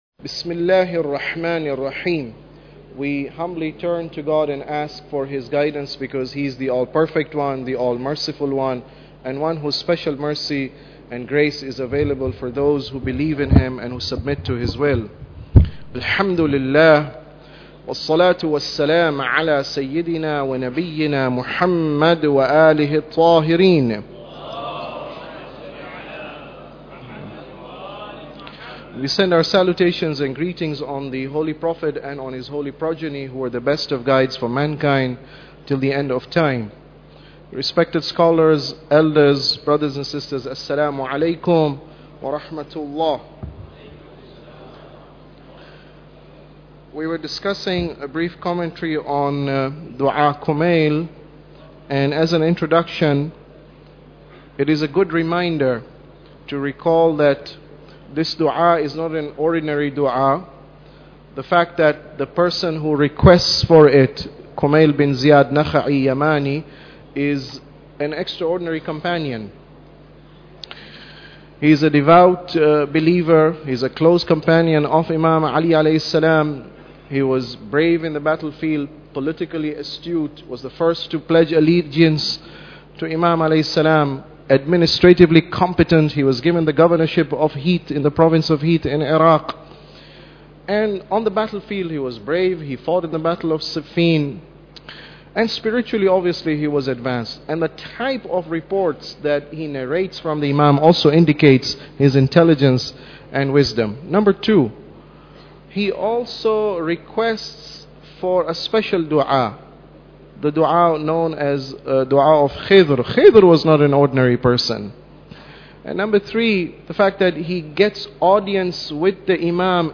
Tafsir Dua Kumail Lecture 7